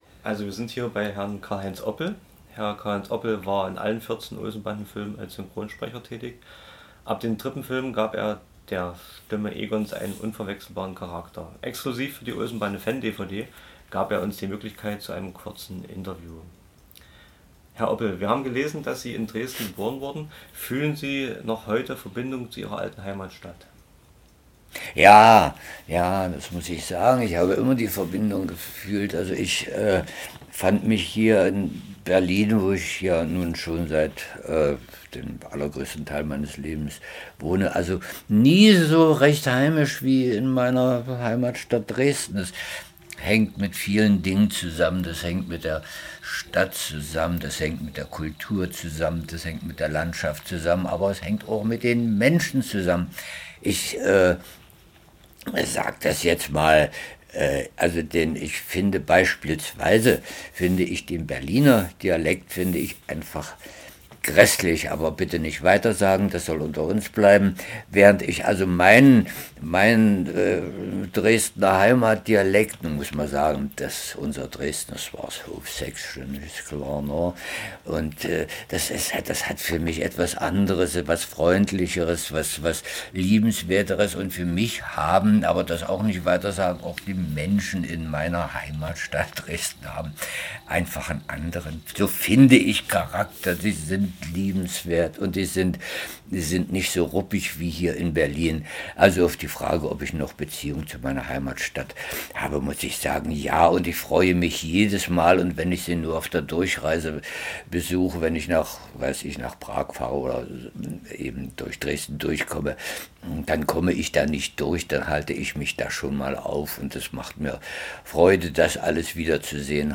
Audiomitschnitt des Interviews:
(Dies war unser allererstes Toninterview mit einem frisch gekauften MD-Recorder. Wir bitten die Qualit�t zu entschuldigen, denn leider haben sich die Schreibger�usche direkt auf das Mikro �bertragen.